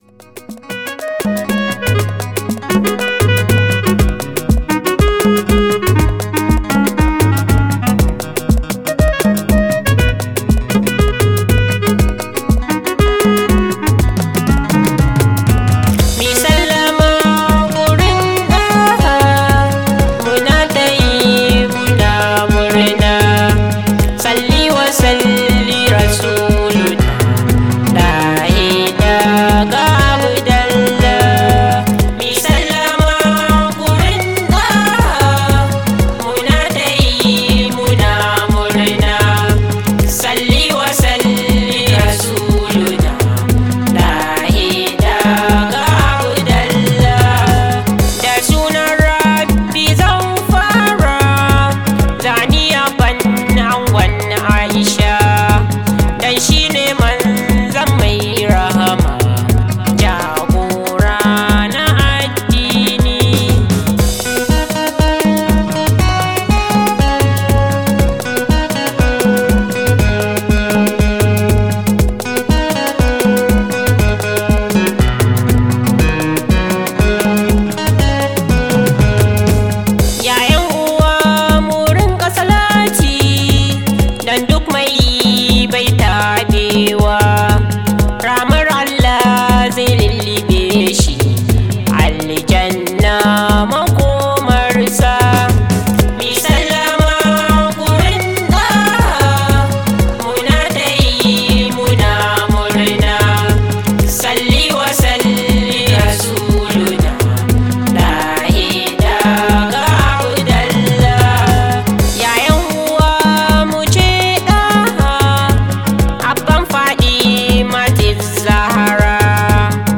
• Genre: Yabon Annabi (S.A.W)